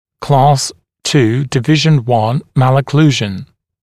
[klɑːs tuː dɪ’vɪʒn wʌn ˌmælə’kluːʒn][кла:с ту: ди’вижн уан ˌмэлэ’клу:жн]II класс, 1 подкласс аномалии прикуса